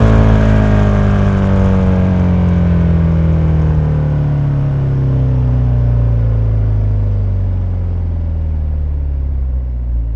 rr3-assets/files/.depot/audio/Vehicles/i4_03/i4_03_decel.wav
i4_03_decel.wav